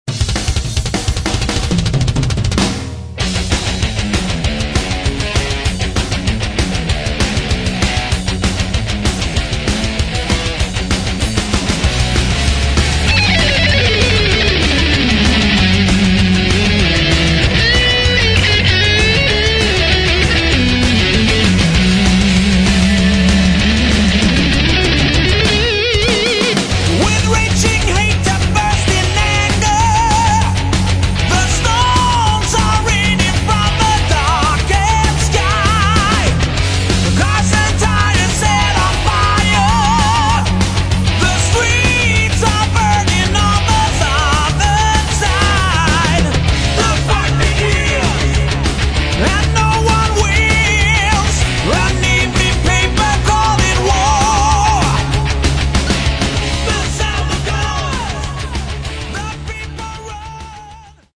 гитара, клавиши, бас
вокал
барабаны